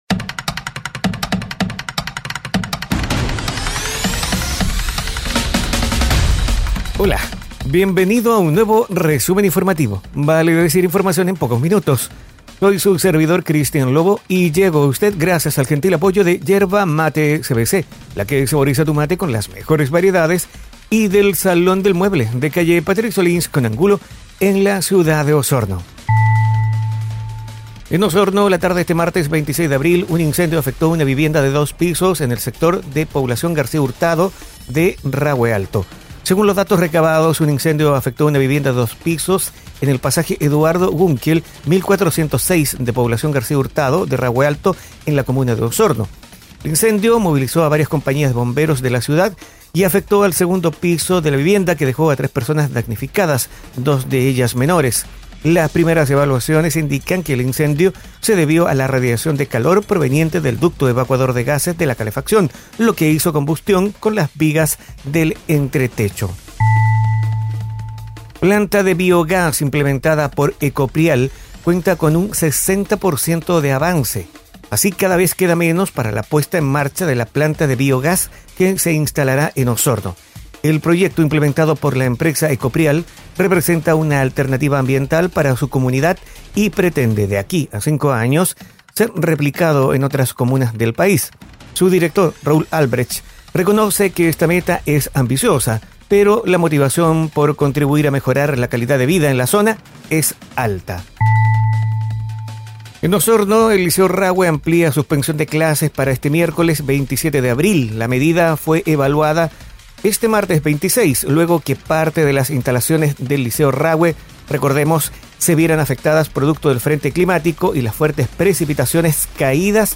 Resumen informativo es un audio podcast con una decena informaciones en pocos minutos, enfocadas en la Región de Los Lagos,